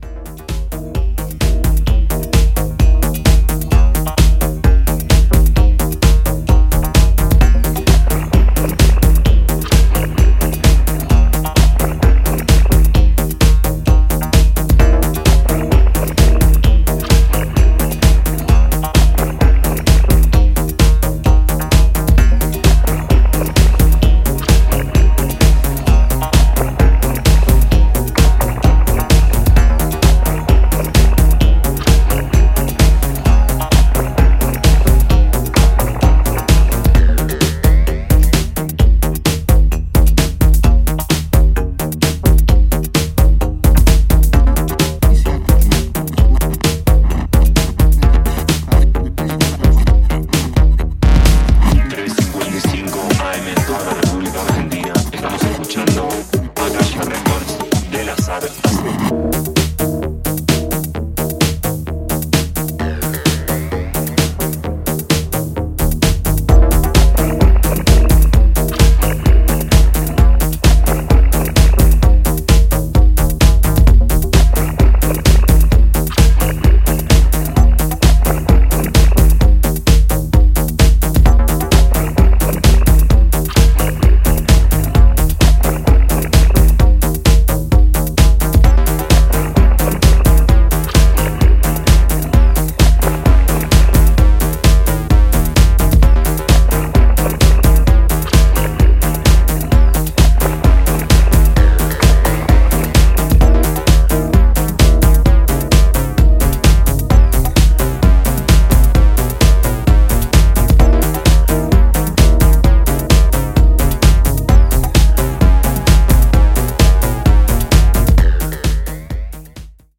three delightful dancefloor dishes